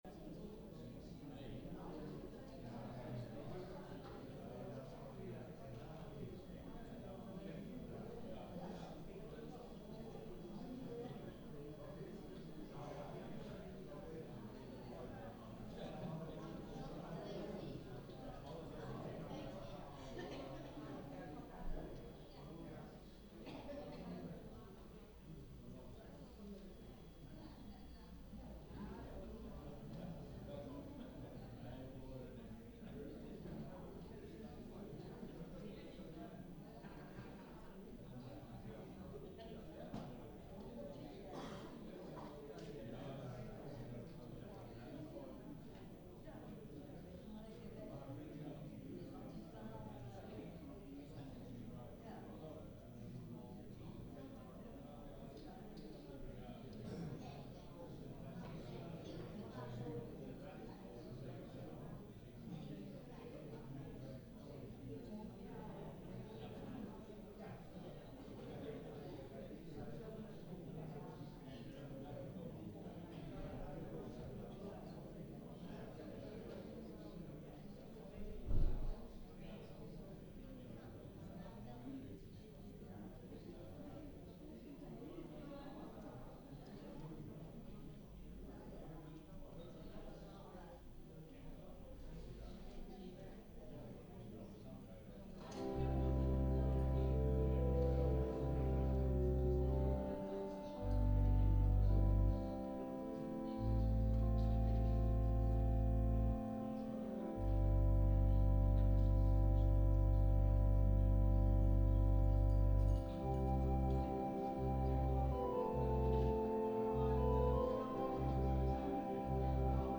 Een kleine impressie vind u hieronder, telkens een video en een audio opname van de dienst.